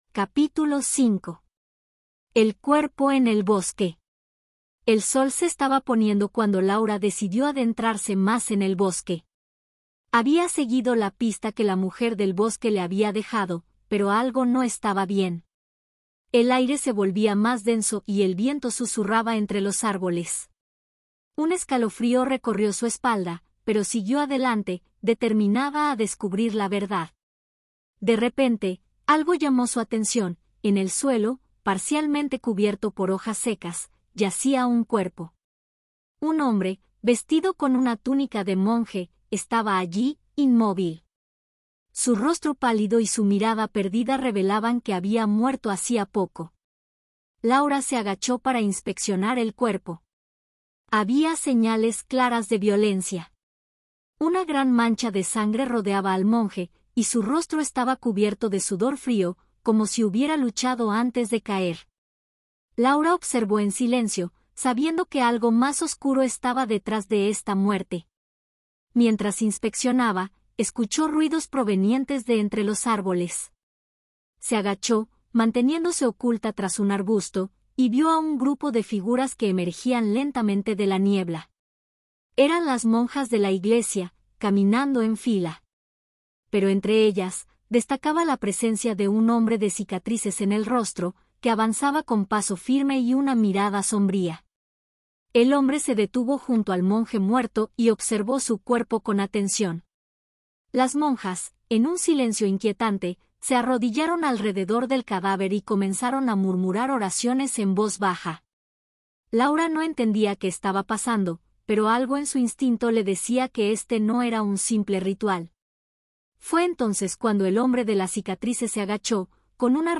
AudioBook El misterio del manuscrito B2-C1 - Hola Mundo